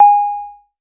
REDD PERC (33).wav